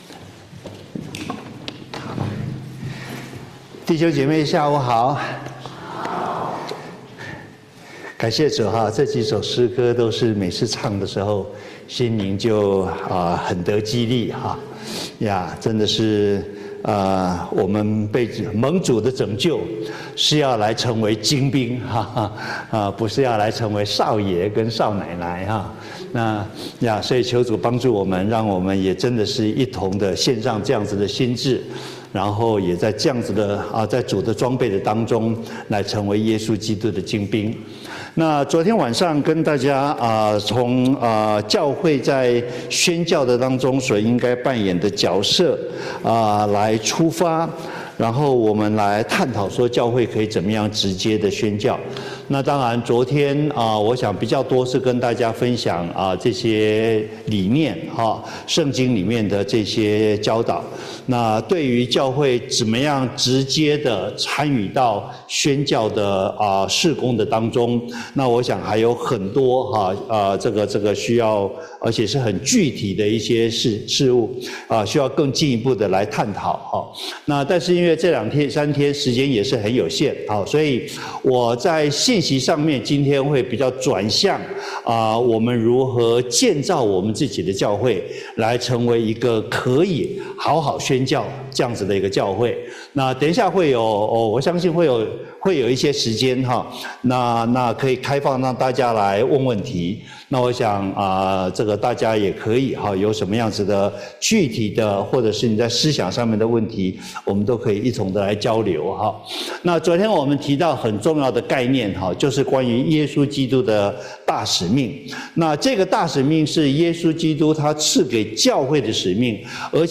Sermons – 第 2 页 – Chinese Christian Church of Baltimore